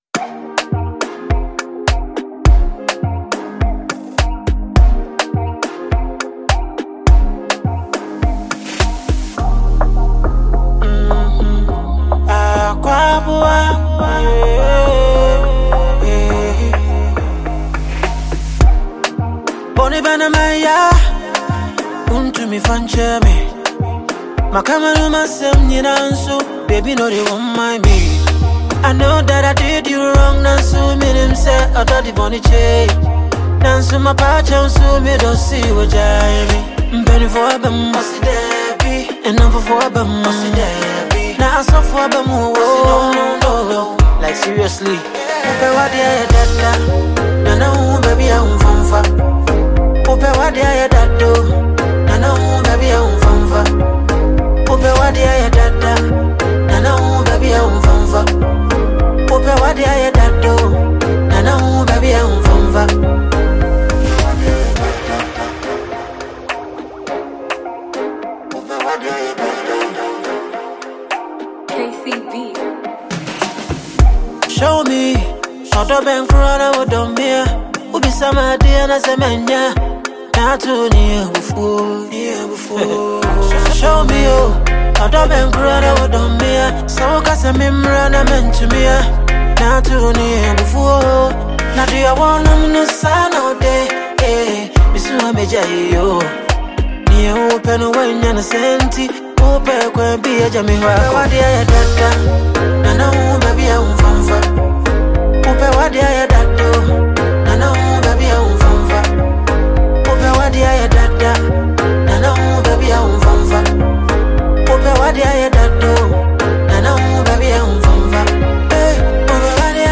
Award winning Sensational Highlife singer and songwriter